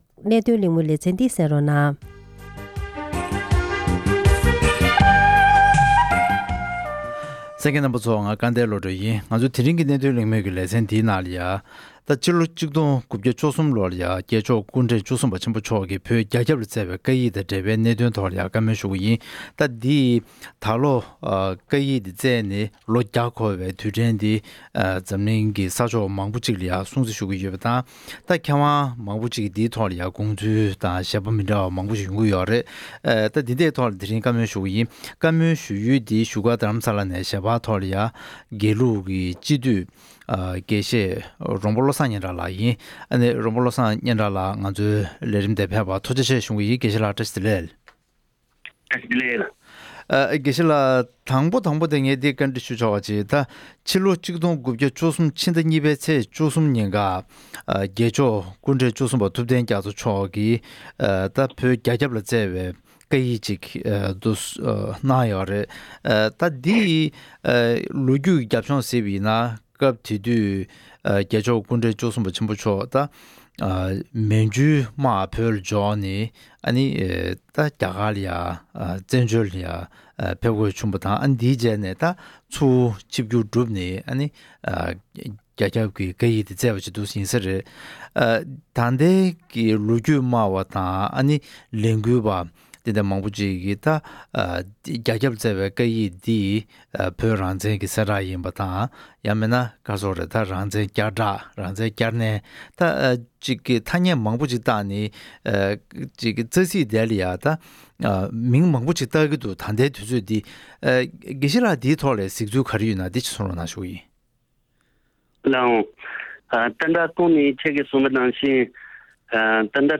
༄༅། །དེ་རིང་གི་གནད་དོན་གླེང་མོལ་ཞེས་པའི་ལེ་ཚན་ནང་།
ཐད་ཀར་གླེང་མོལ་ཞུས་པ་ཞིག་གསན་རོགས་གནང་།།